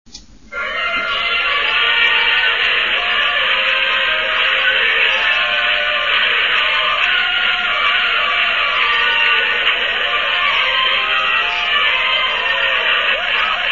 Chant nº1 des supporters 40 ko
supporters1.mp3